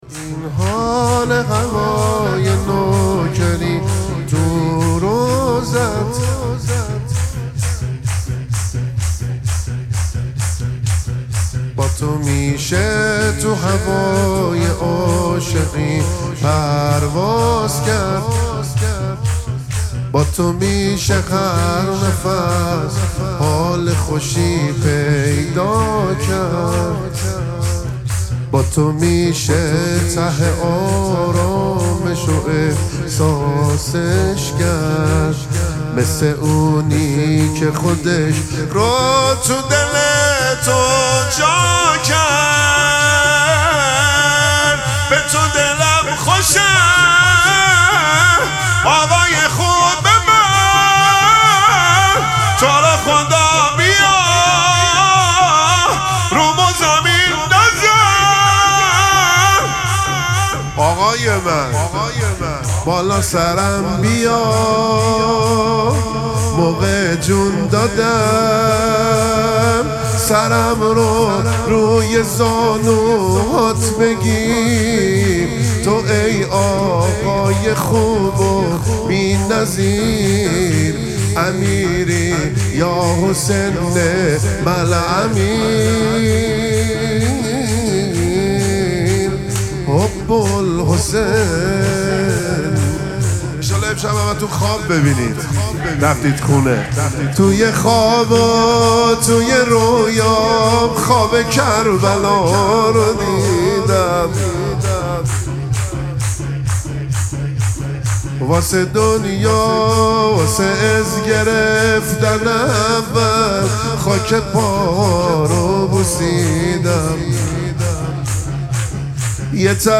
مراسم مناجات شب هفدهم ماه مبارک رمضان
شور
مداح